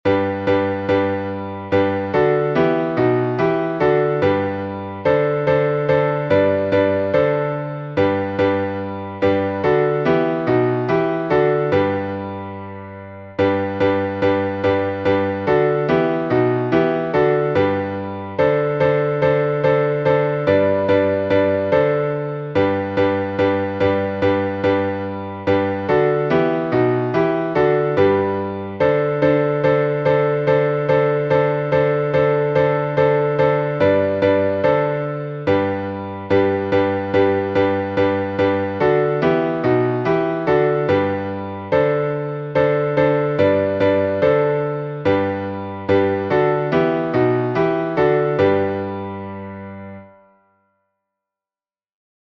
Тропари на «Бог Господь» — глас 7 Вернуться в меню · Хвалите имя Господне Якутская и Ленская епархия Якутская и Ленская епархия PDF · MP3 · Видео · В начало Наверх · Вернуться в меню · Хвалите имя Господне